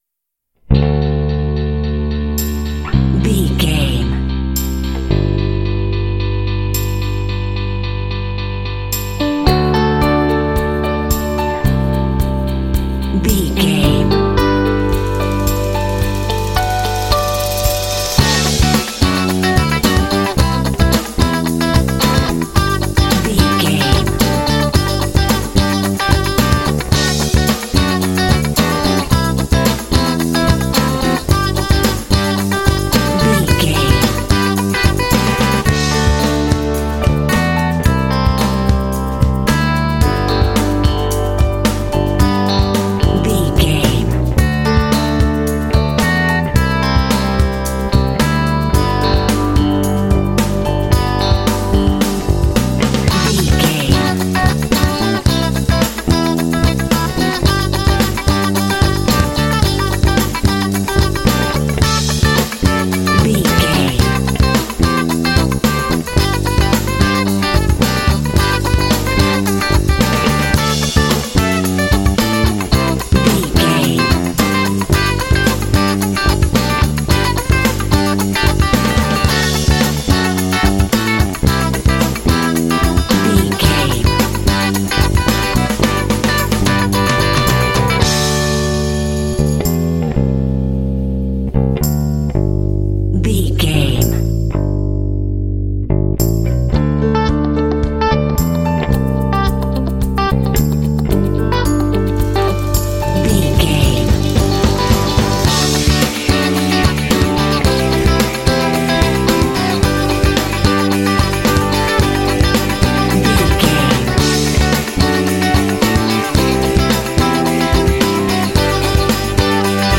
Uplifting
Ionian/Major
optimistic
happy
bright
piano
electric guitar
drums
bass guitar
percussion
pop
contemporary underscore
rock
indie